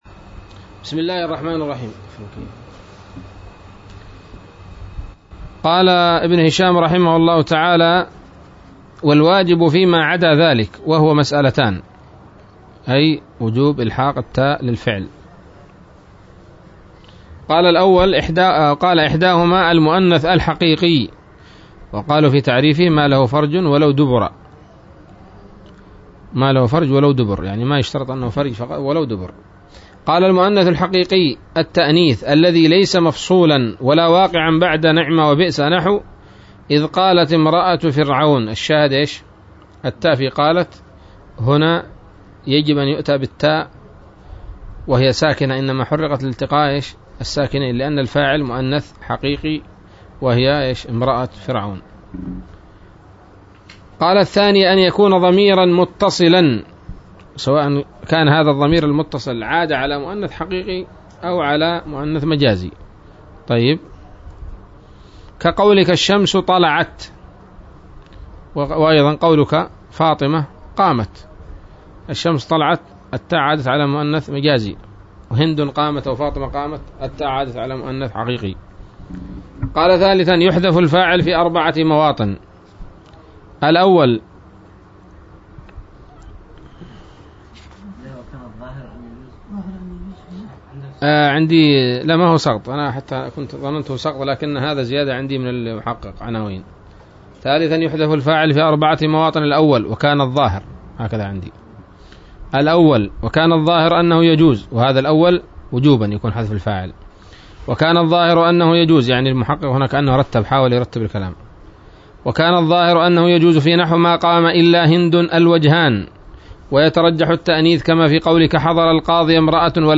الدرس الخامس والسبعون من شرح قطر الندى وبل الصدى